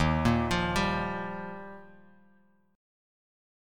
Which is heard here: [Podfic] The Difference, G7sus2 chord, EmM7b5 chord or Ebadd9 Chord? Ebadd9 Chord